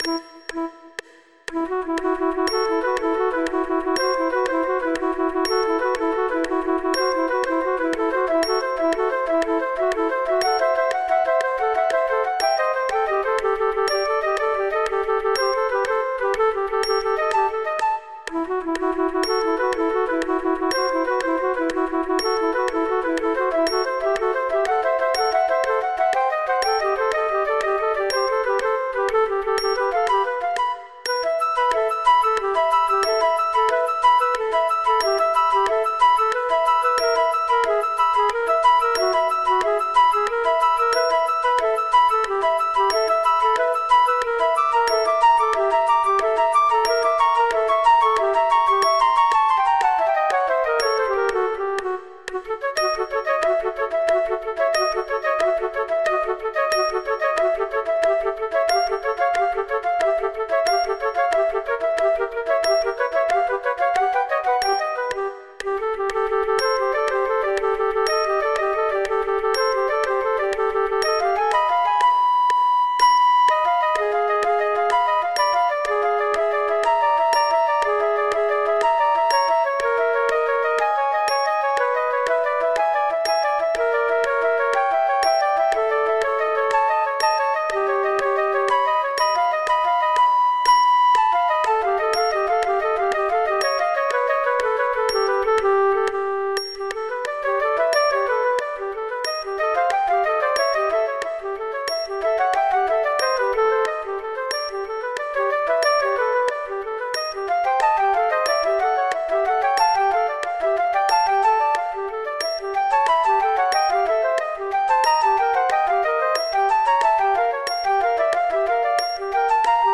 This trio for three flutes is full of aural illusions.
With metronome clicks (and apologies for a strange metronome artefact right at the beginning),
and with the third flute missing there are